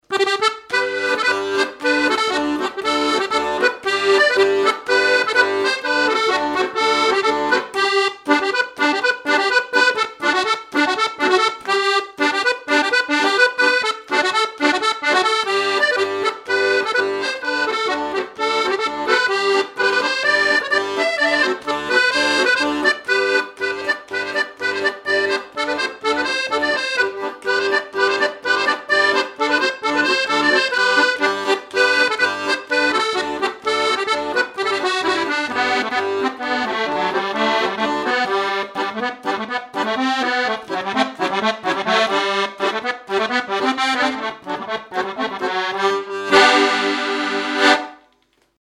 Thème : 1074 - Chants brefs - A danser Résumé : C'est la fille de la meunière, qui dansait avec Nicolas.
Fonction d'après l'analyste danse : gigouillette
Catégorie Pièce musicale inédite